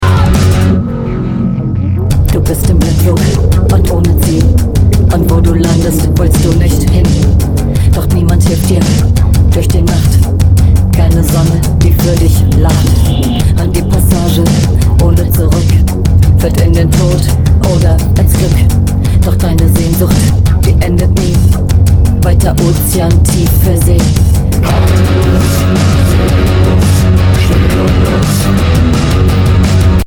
EBM, Electro, Neue Deutsche Todeskunst
Das gesprochene Wort rockt!
Minimal-Electro mit pumpenden Bässen und peitschenden Drums
Musikalisch aggressiver als die Vorgänger